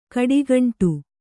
♪ kaḍigaṇṭu